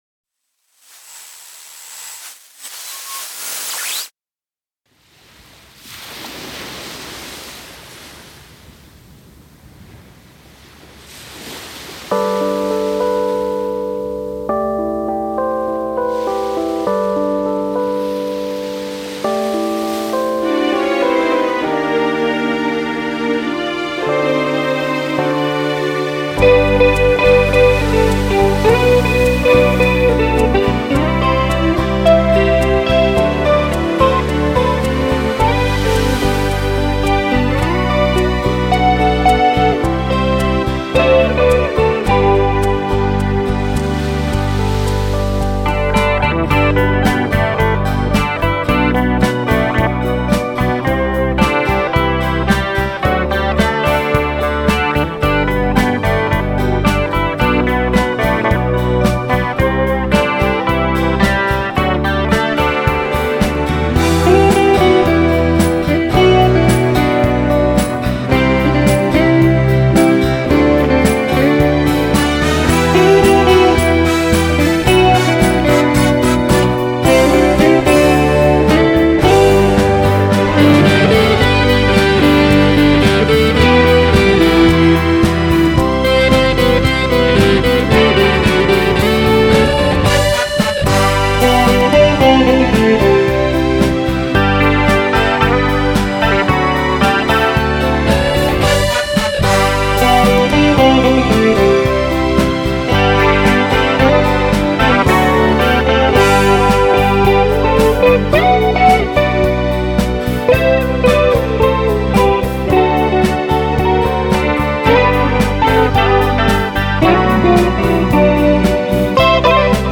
ギター・インストゥルメンタル